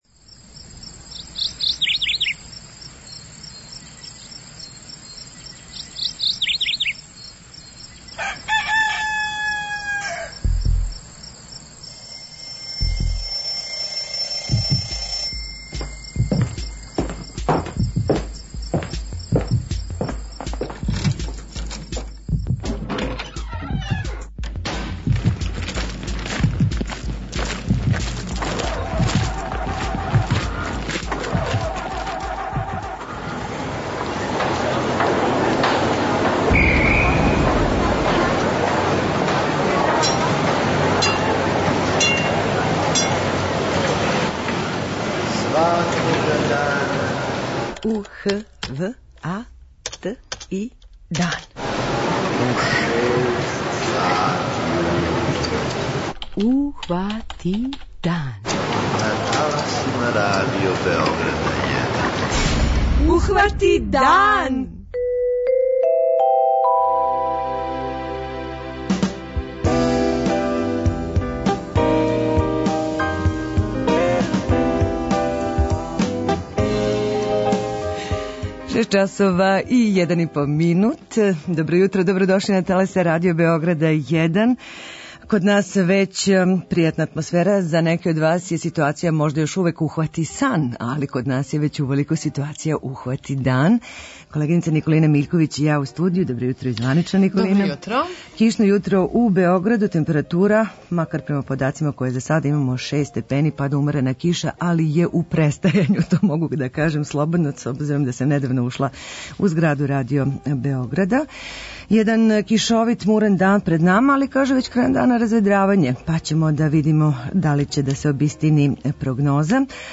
- у Бечеју додељењо шест кућа-домаћинстава избеглима и интерно расељенима - настављена је добра пракса, каже председник Општине Вук Радојевић, наш гост телефоном;